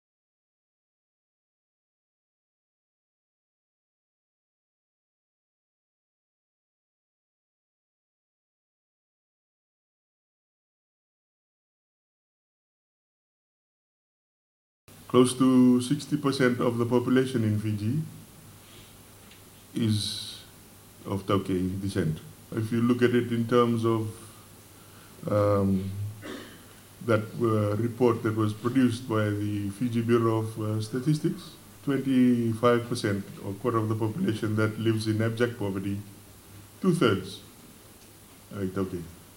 Addressing the audience at the commencement of a three-day symposium held at the Suva Civic Center today, Deputy Prime Minister and Minister for Trade Manoa Kamikamica underscored the concerning reality that, despite owning 90 percent of the land in Fiji, the i-Taukei community still faces business-related disparities.